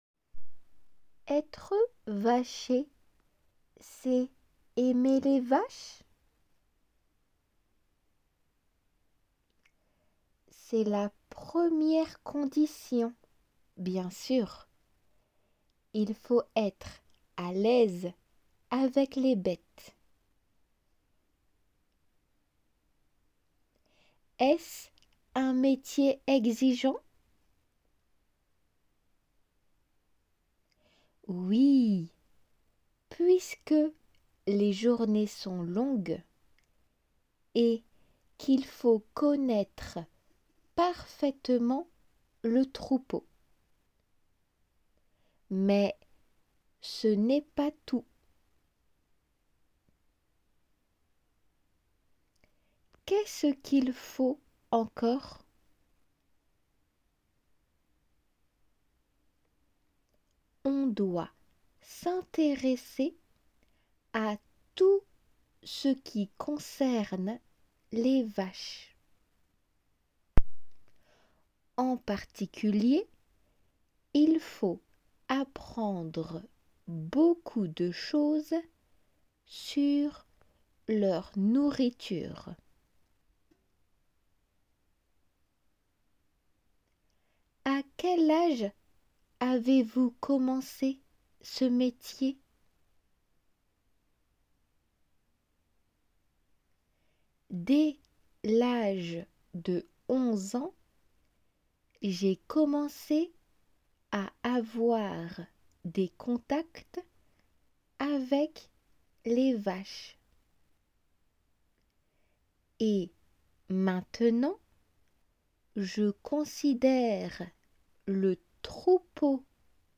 聞き取り用音声　それに続く質問
聞きとり用の音声は　5セット　会話体で構成されている傾向。